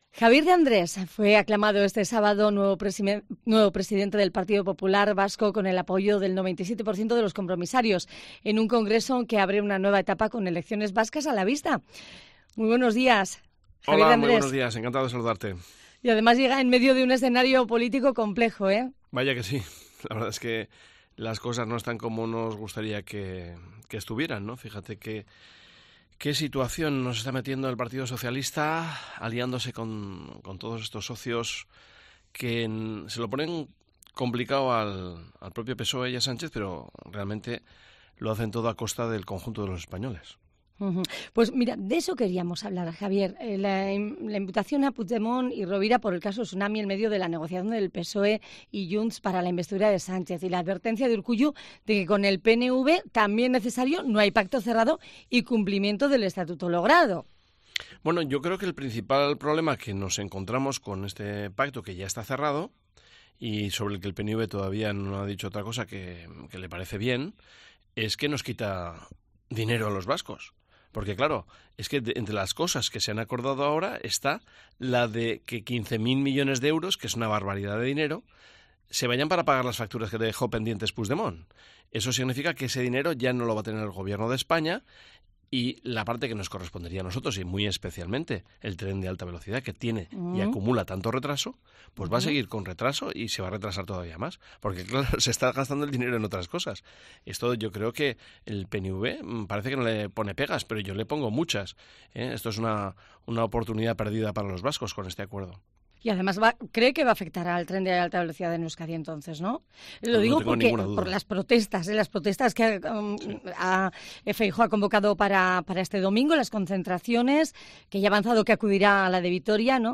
Entrevista a Javier De Andrés en COPE Euskadi